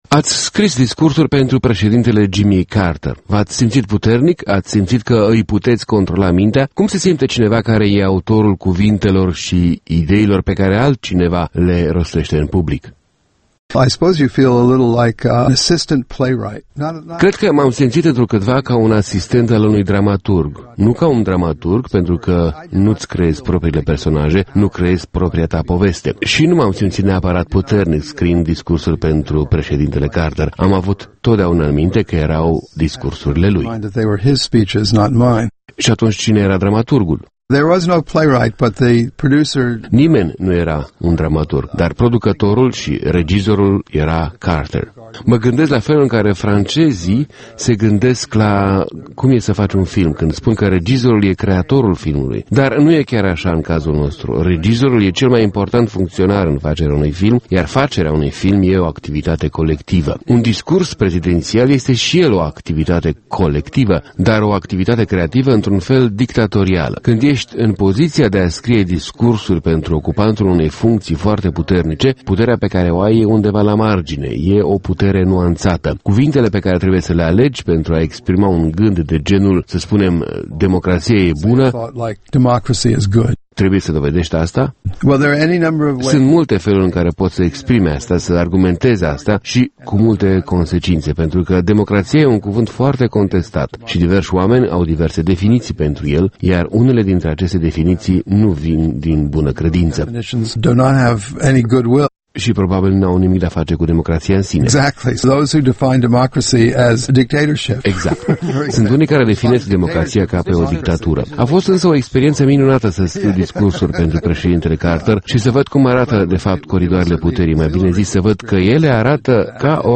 Interviu cu Hendrik Hertzberg, comentator politic la The New Yorker